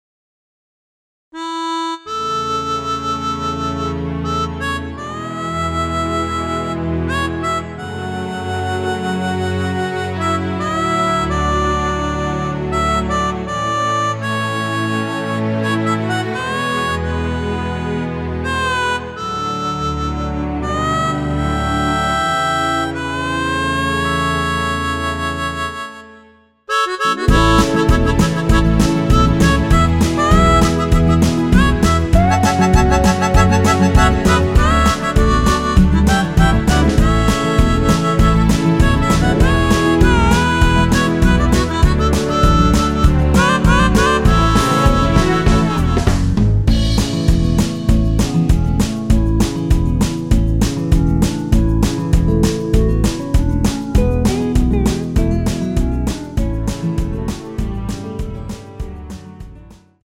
원키에서(-3) 내린 멜로디 포함된 MR 입니다.(미리듣기 확인)
앞부분30초, 뒷부분30초씩 편집해서 올려 드리고 있습니다.
중간에 음이 끈어지고 다시 나오는 이유는